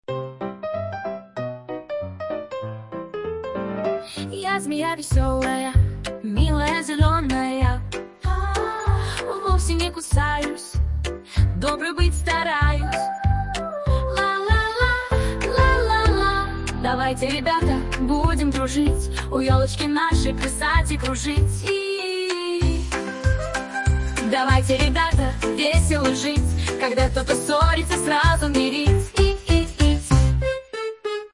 Детская песенка на Новый Год Змеи
Фрагмент 2-го варианта исполнения: